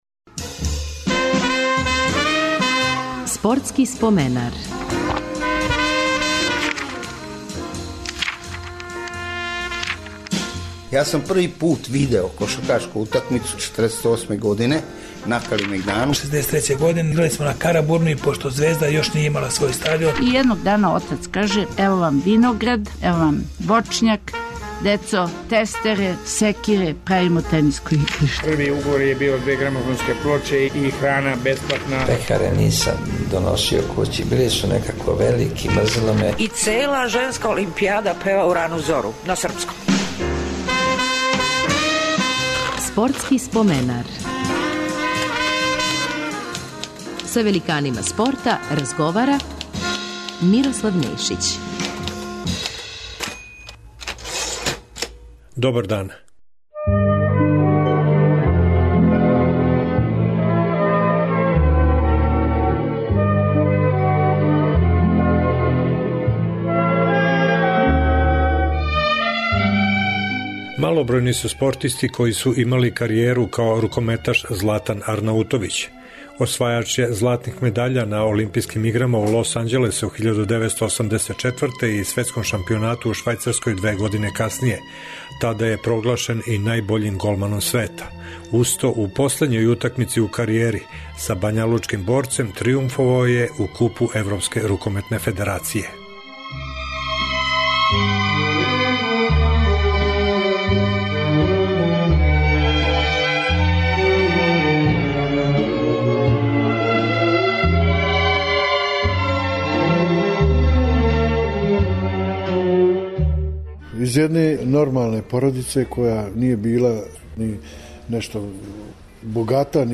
Гост ће бити рукометни голман Златан Арнаутовић.